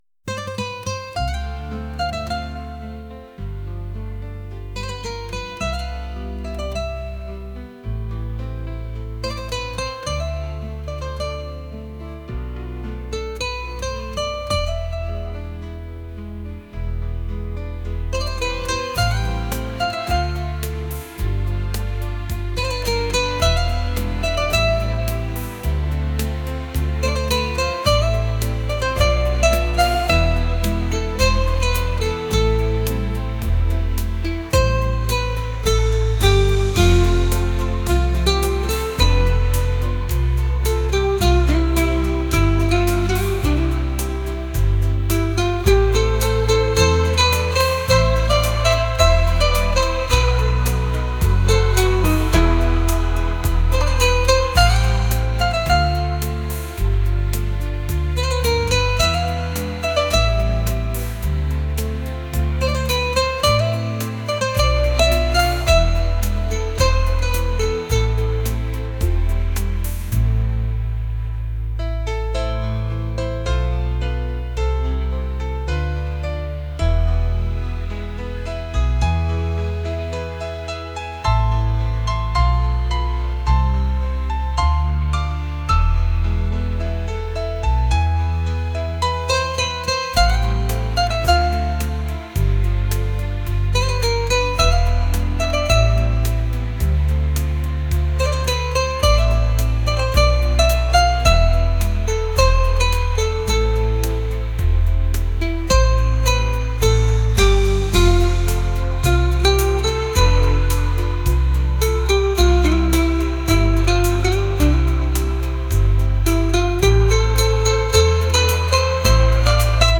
pop | acoustic | cinematic